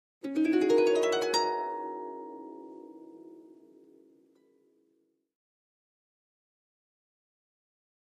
Harp, High Strings 7th Chords, Short Ascending Gliss, Type 1